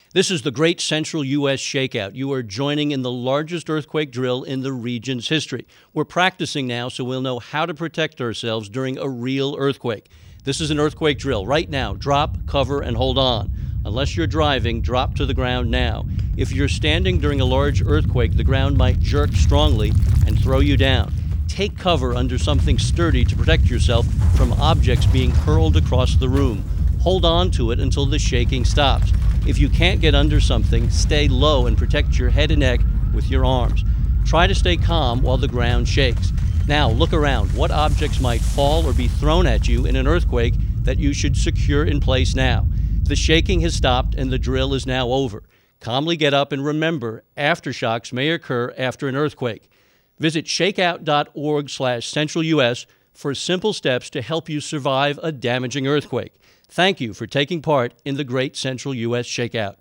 Download: Narration and Sound Effects, MP3 Format
• For best performance, listen to the recording through external speakers (there is a rumble sound that may not be heard through most internal computer speakers)
ShakeOut_60sec_Drill_Broadcast_English.mp3